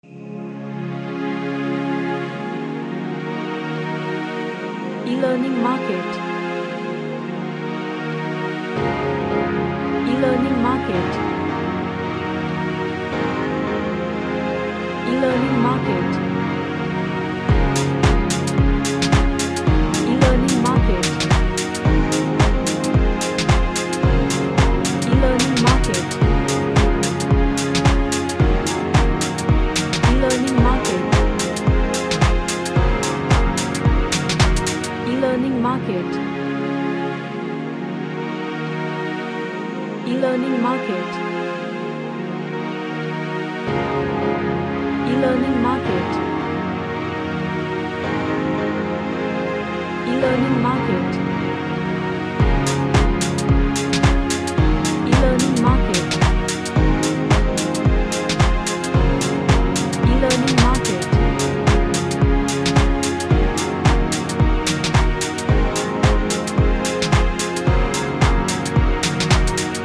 A pop track with nice groove.
Happy